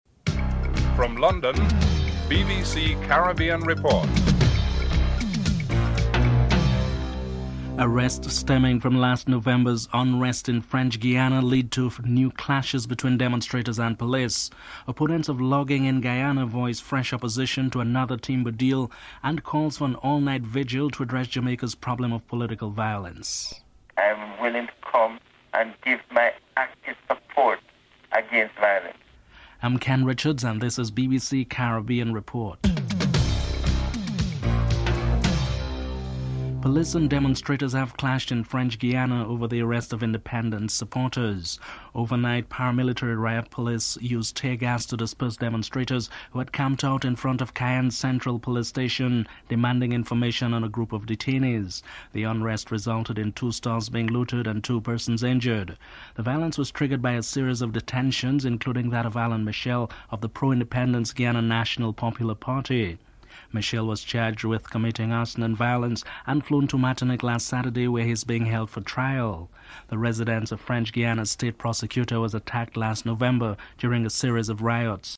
1. Headlines (00:00:00:36)